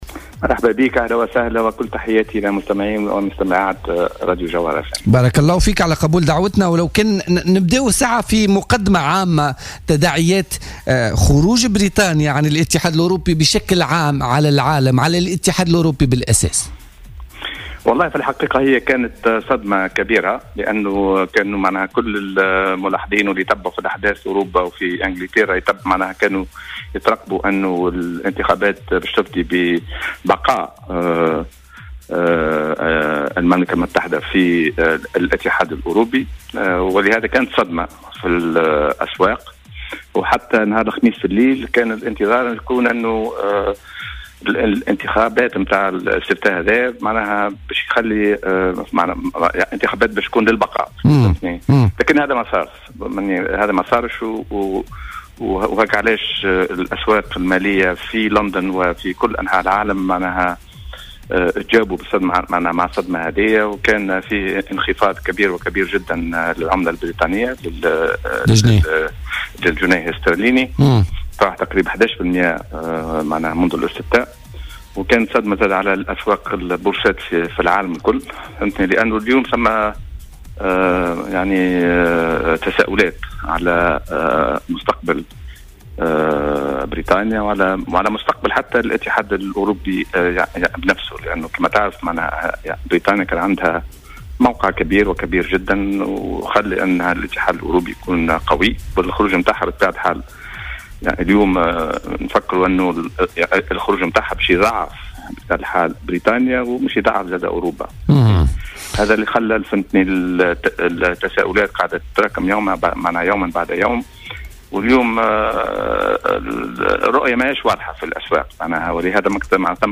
وتوقّع في مداخلة له في برنامج "الحدث" على "الجوهرة أف أم" صعودا للدينار التونسي لكن بنسبة بسيطة ليرتد من جديد انطلاقا من سياسة الصرف التي ينتهجها البنك المركزي.